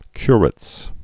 (kyrĭts)